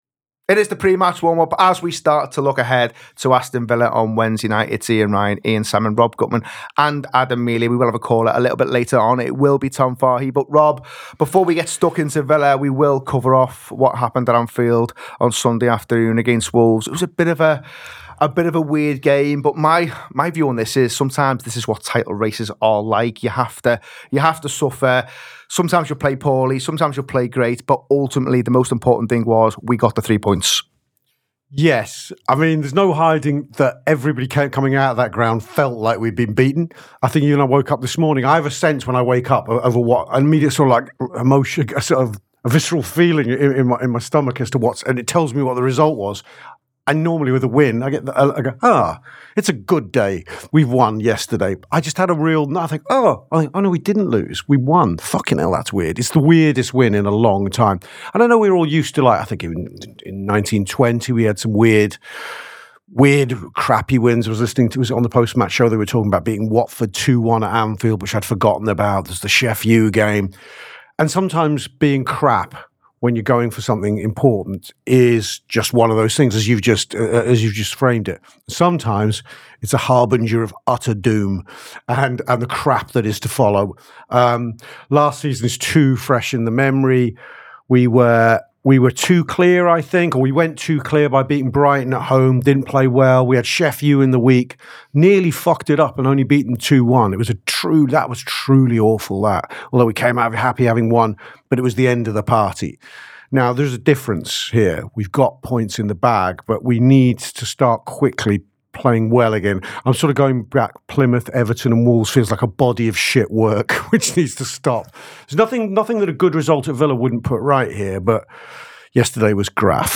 Below is a clip from the show – subscribe for more pre-match build up around Aston Villa v Liverpool…